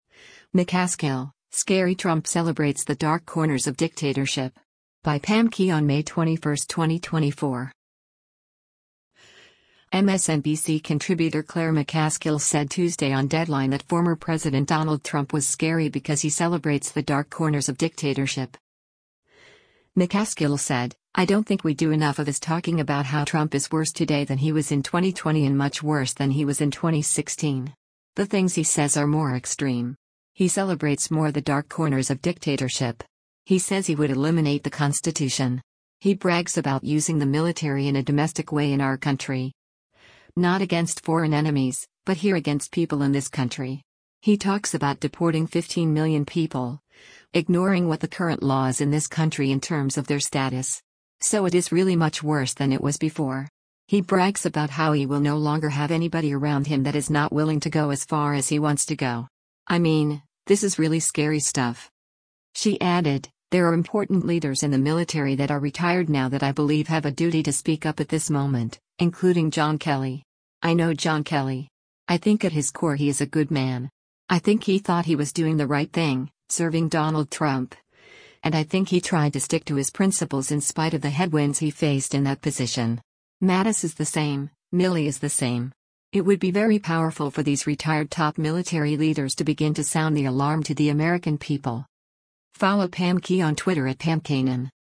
MSNBC contributor Claire McCaskill said Tuesday on “Deadline” that former President Donald Trump was “scary” because he celebrates “the dark corners of dictatorship.”